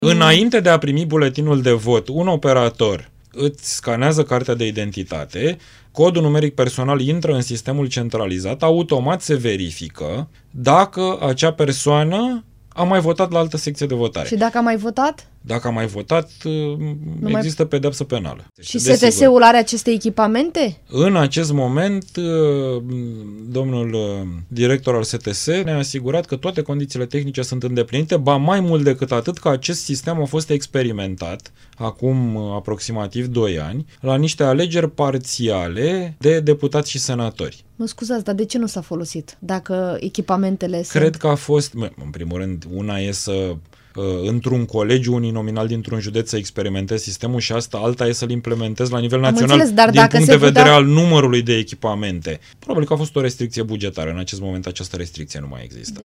Invitat la Interviurile Europa FM, președintele Comisiei pentru Cod Electoral, deputatul liberal Mihai Voicu, a explicat că STS a testat deja scanerele încă de acum doi ani, iar acestea vor fi folosite la alegerile locale de anul viitor, dacă și Camera Deputaților votează această modificare a legii, aprobată deja de Senat.
23-apr-13-Voicu-interviu-nu-se-mai-poate-vota-la-dublu.mp3